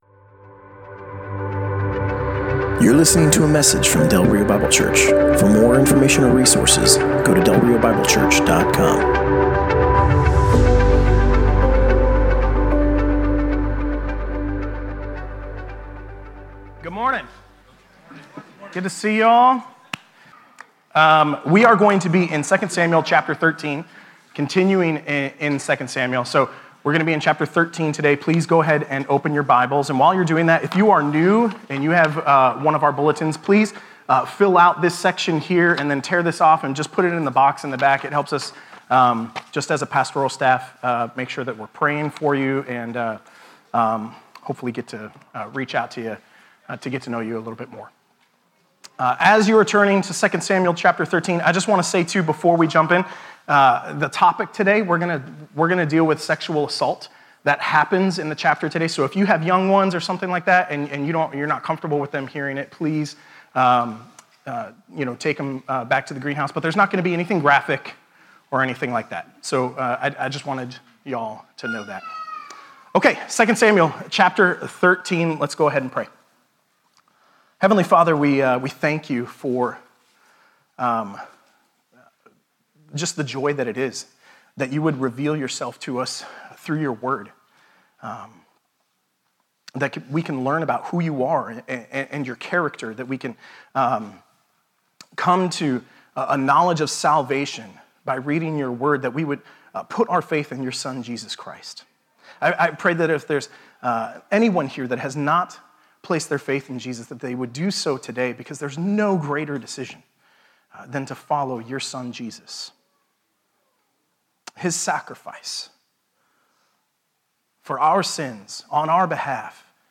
Passage: 2 Samuel 13: 1-22 Service Type: Sunday Morning